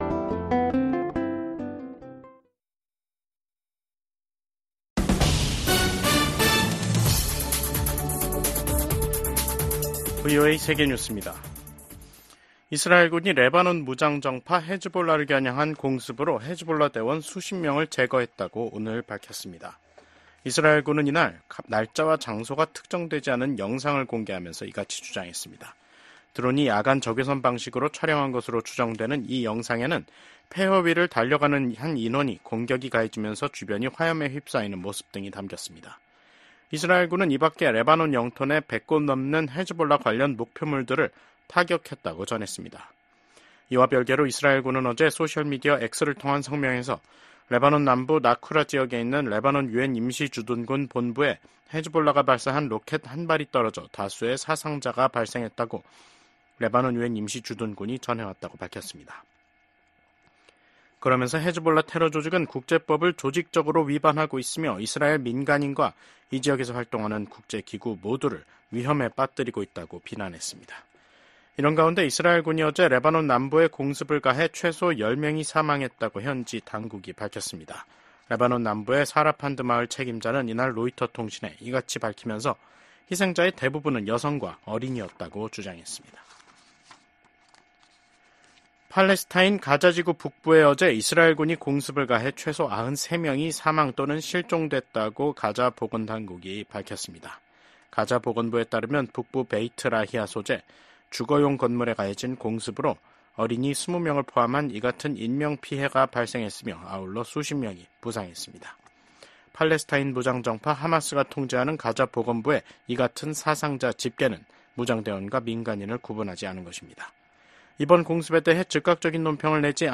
VOA 한국어 간판 뉴스 프로그램 '뉴스 투데이', 2024년 10월 30일 2부 방송입니다. 미국 국방부는 러시아에 파견된 북한군 일부가 이미 우크라이나에 가까운 러시아 쿠르스크에 주둔 중이라고 밝혔습니다. 윤석열 한국 대통령은 볼로디미르 젤렌스키 우크라이나 대통령과 쥐스탱 트뤼도 캐나다 총리와 연이어 통화를 하고 북한의 러시아 파병에 대한 공동 대응 의지를 밝혔습니다.